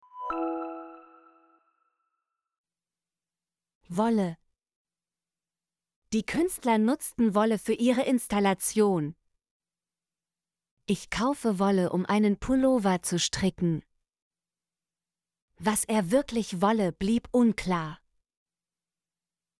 wolle - Example Sentences & Pronunciation, German Frequency List